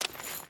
Water Chain Run 1.ogg